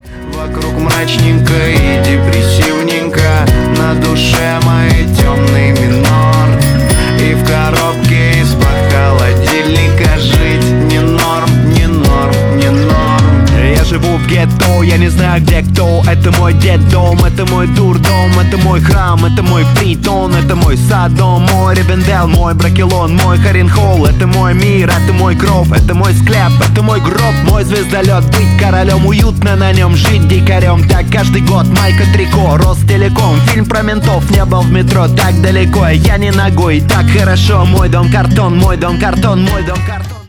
Stereo
Рэп и Хип Хоп